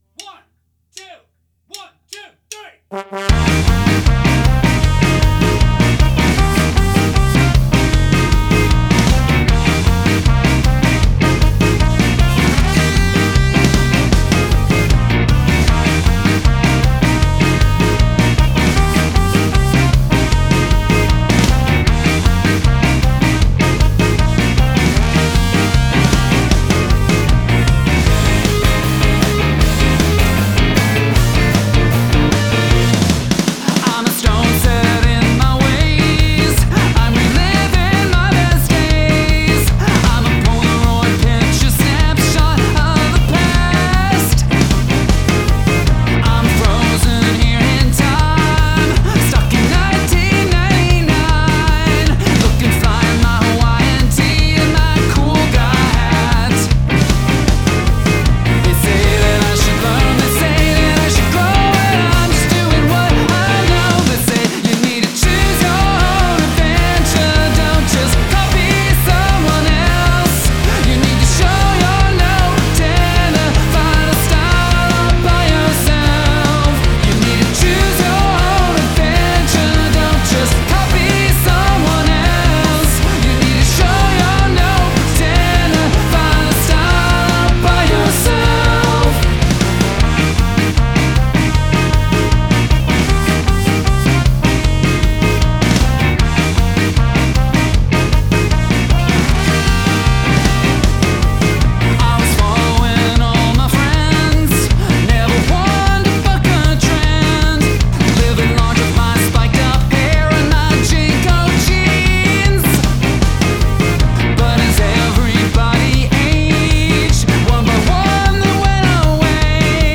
Ska or Glitch (not both)